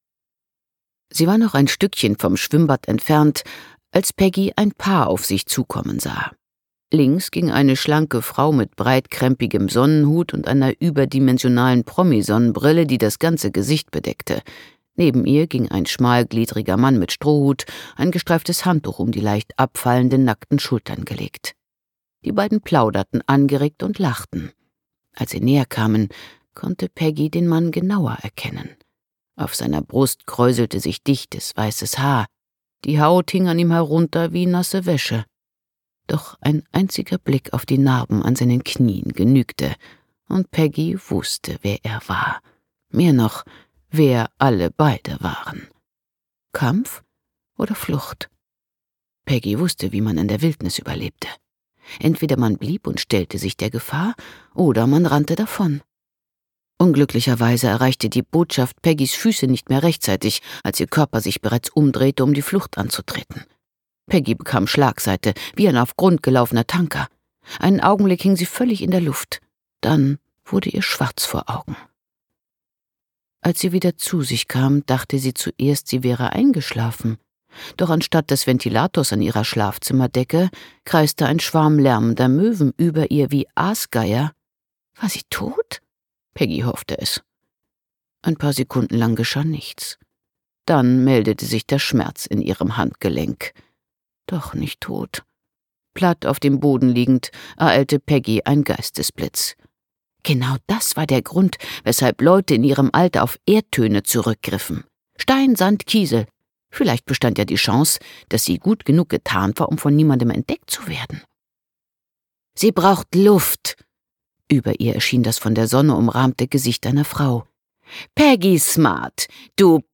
Autorisierte Lesefassung